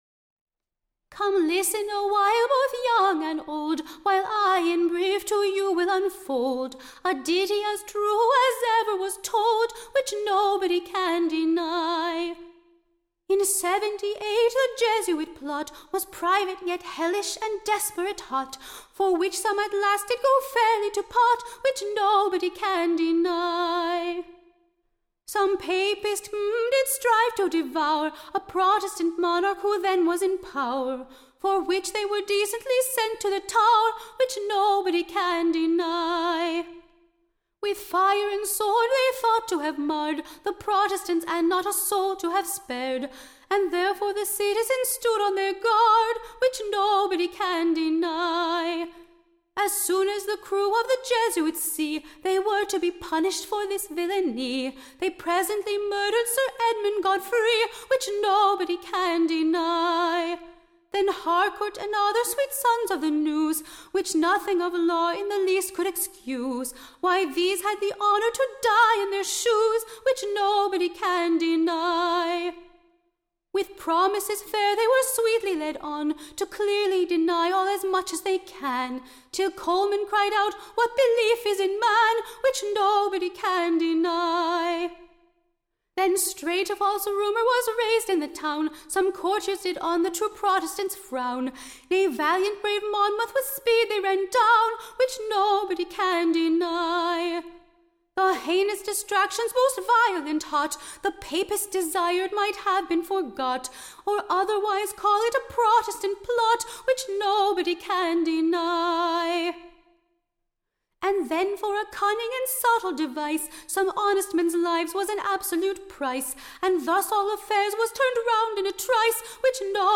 Recording Information Ballad Title A View of the POPISH-PLOT; / OR, / A Touch of the Cunning Contrivance of the Romish Faction, / from the Year Seventy-Eight, to this late Time.